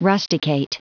Prononciation du mot rusticate en anglais (fichier audio)